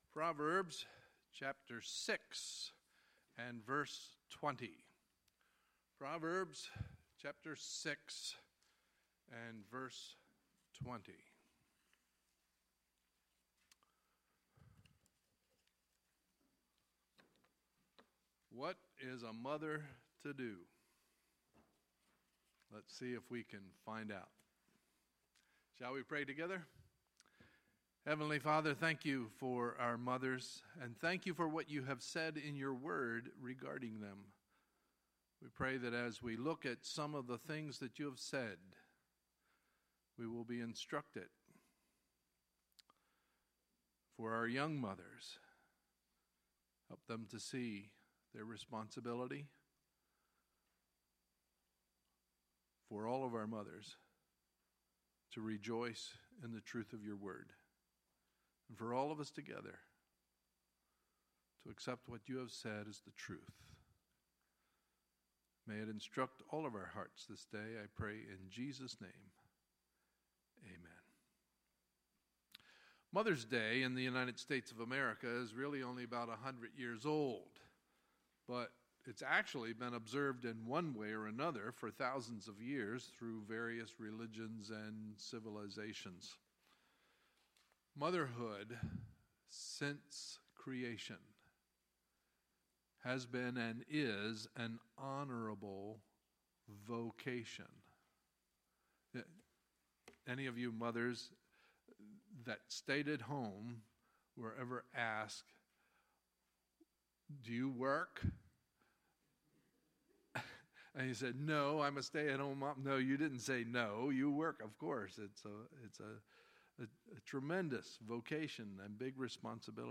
Sunday, May 14, 2017 – Sunday Morning Service
Sermons